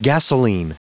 Prononciation du mot gasolene en anglais (fichier audio)
Prononciation du mot : gasolene